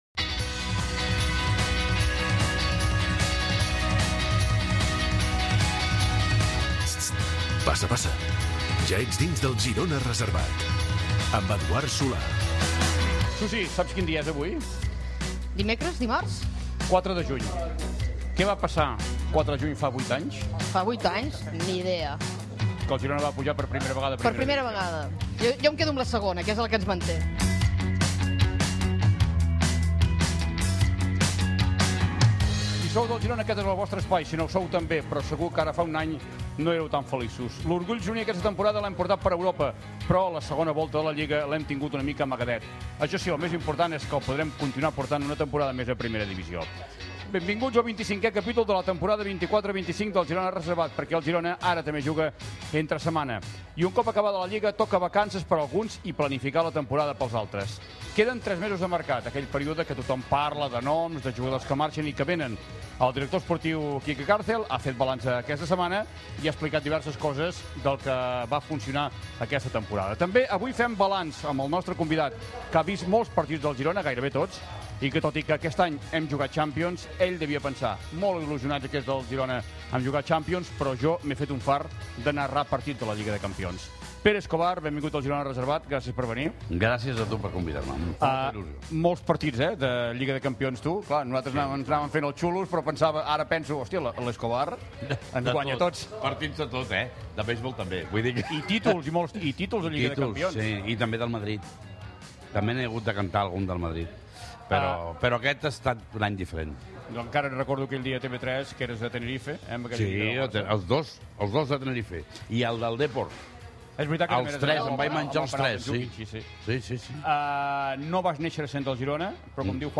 609e8fe573b0340be086d4d1da0bc87cd2061fa3.mp3 Títol Catalunya Ràdio Emissora Catalunya Ràdio Cadena Catalunya Ràdio Titularitat Pública estatal Nom programa Girona reservat Descripció Programa fet des del bar Da Vinci de Girona.
Esportiu